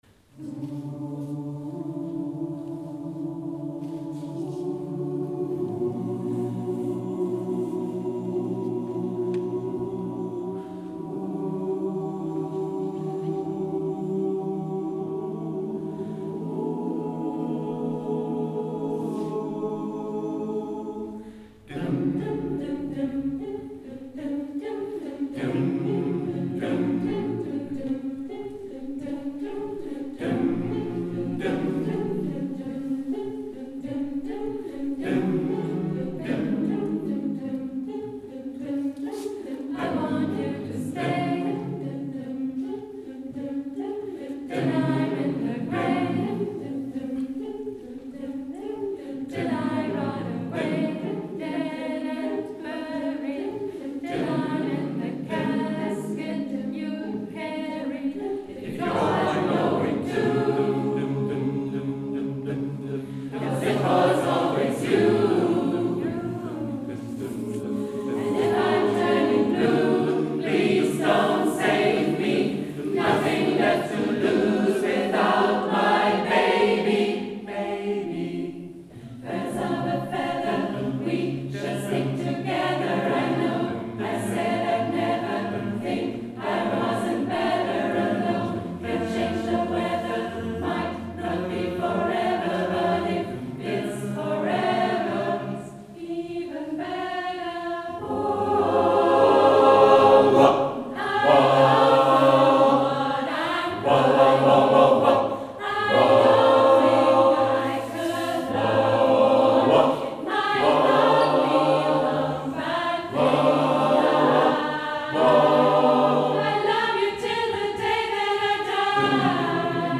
Konzerte – Bonn A Capella
Konzertaufnahmen
Konzert "Evening Rise" - Pauluskirche, Bonn Friesdorf, 2025